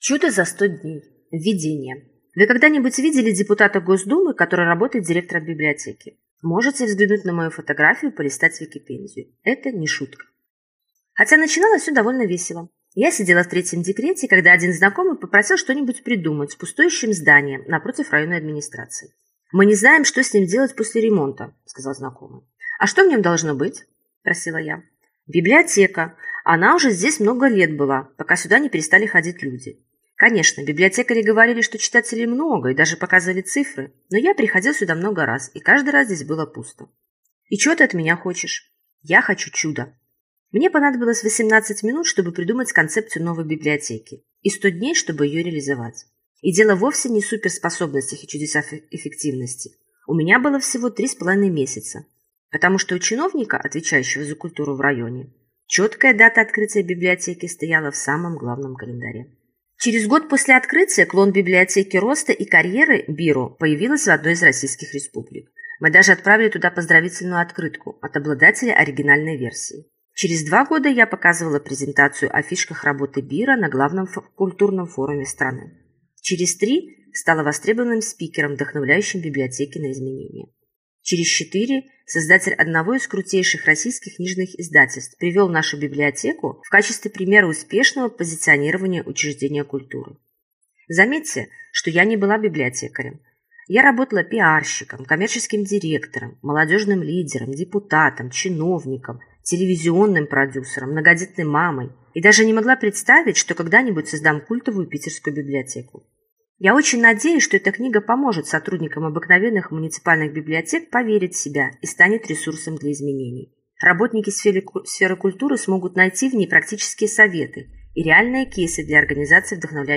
Аудиокнига Чудо за 100 дней. Иcтория создания одной библиотеки Санкт-Петербурга | Библиотека аудиокниг